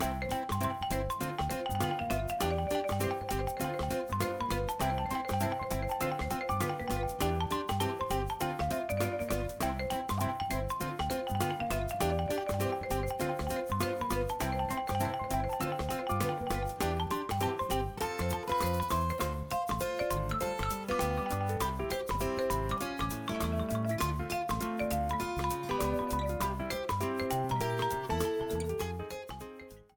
Ripped from game
Fair use music sample